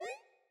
wormHit.mp3